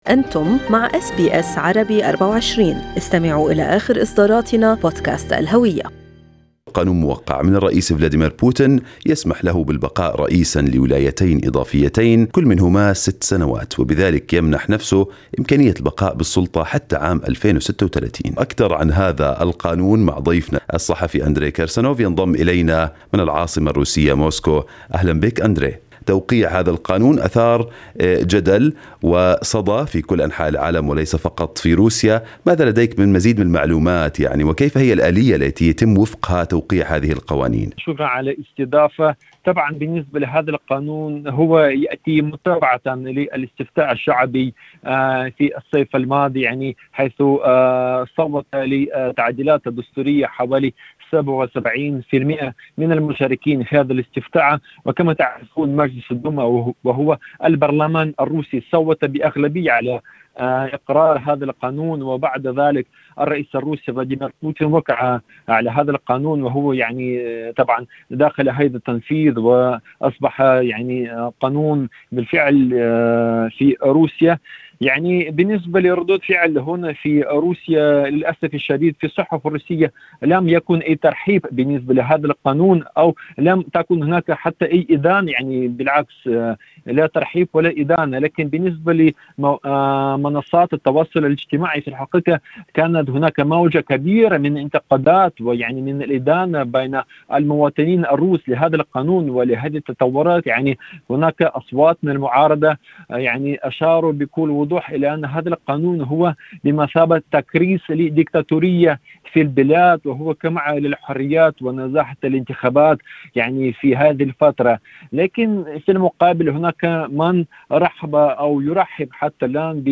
"يصفونه بالزعيم القوي": صحفي روسي ناطق بالعربية يشرح أسباب شعبية بوتين بين العرب